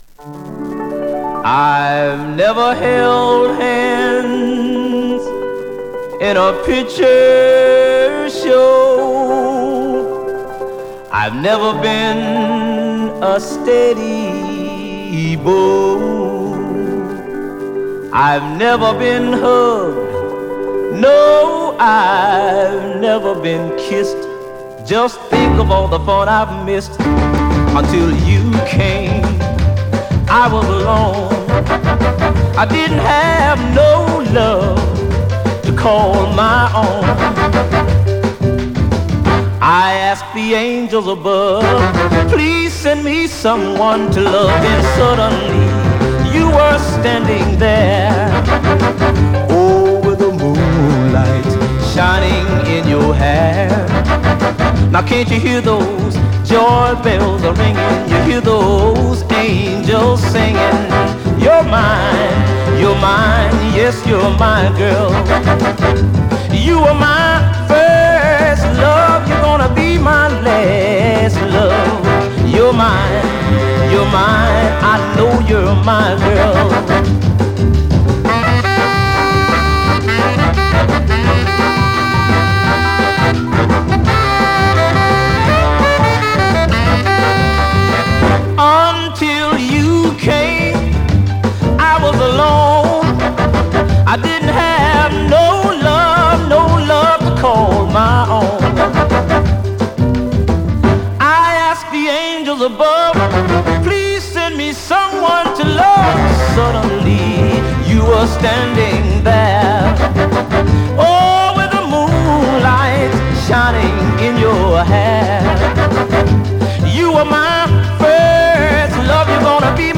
Rockin' R&B Jive